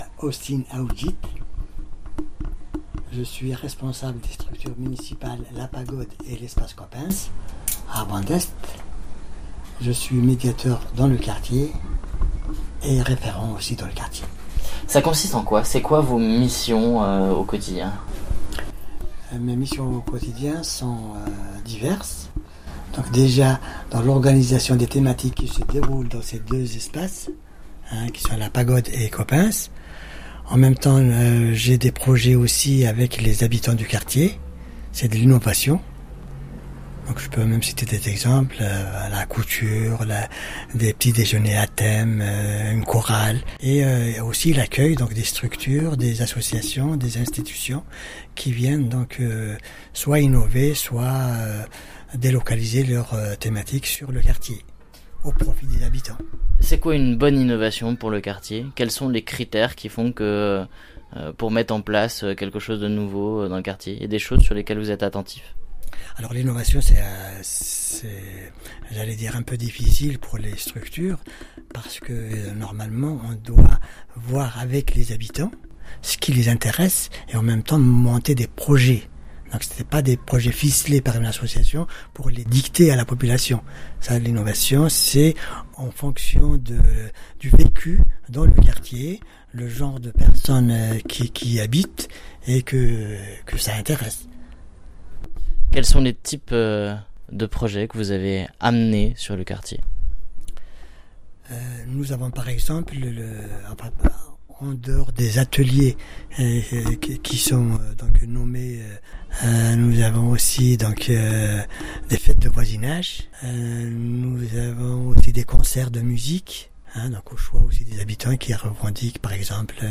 Dans le cadre du Projet Cité Cap, Radio Campus Lorraine réalise une trentaine de portraits radio d’habitants du quartier Vand’Est. La restitution de ces interviews se fera le 23 juin 2018 lors de l’événement Vis dans ta ville à Vandoeuvre-lès-Nancy.